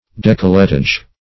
D'ecolletage \D['e]`col`le*tage"\ (d[=a]`k[-o]`l'*t[.a]zh), n.